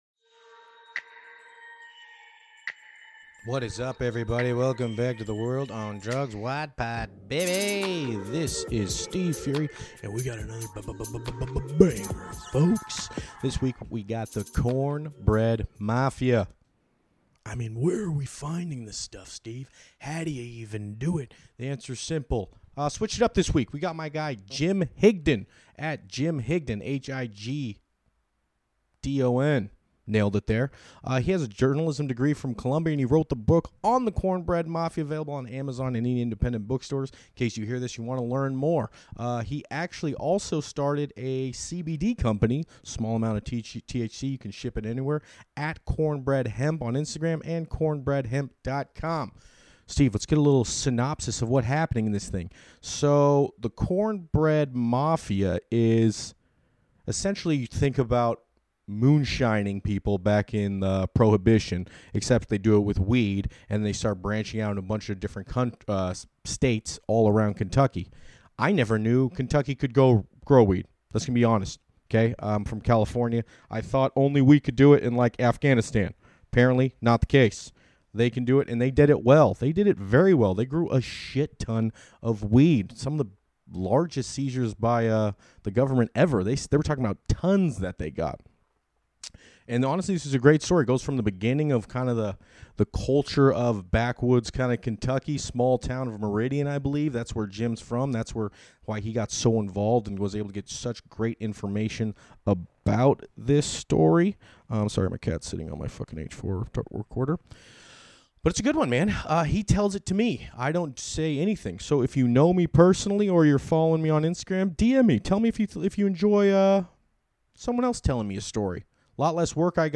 this is the first ep we have a guest telling ME whats going on with the story.